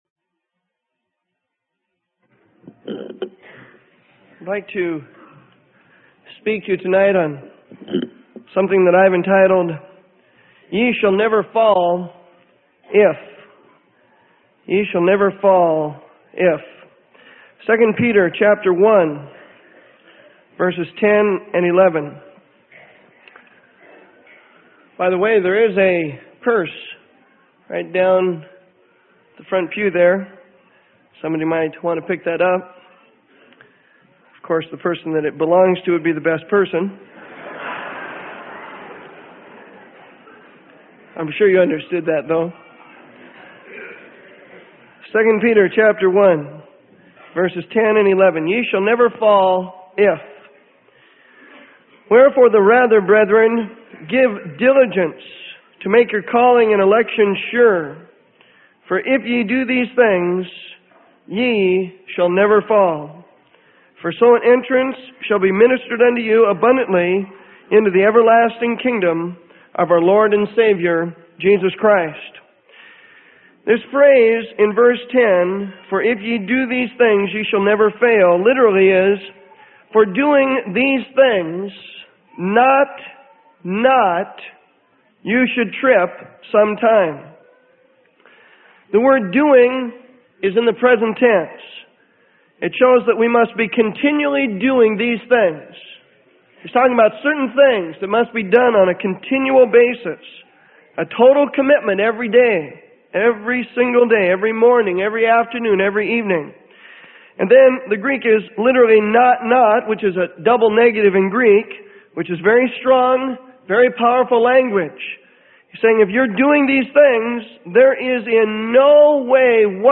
Sermon: Ye Shall Never Fall If...